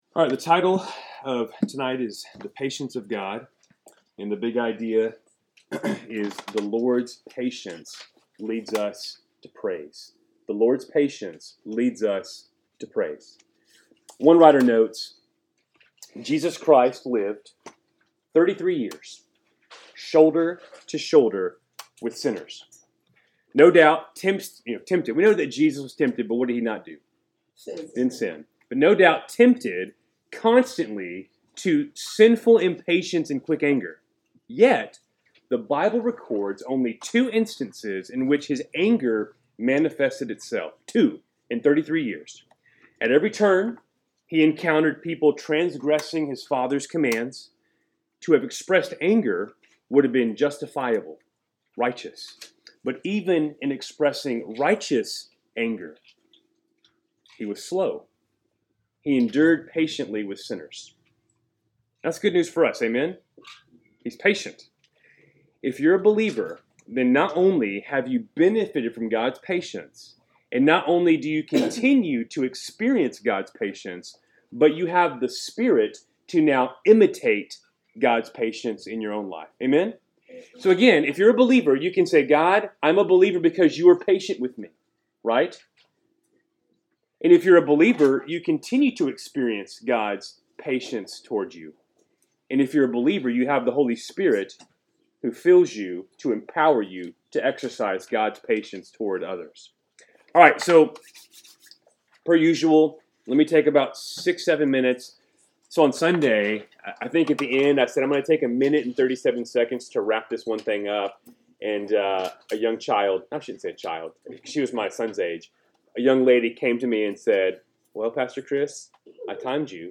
3-19-25-Wednesday-Night-Bible-Study.mp3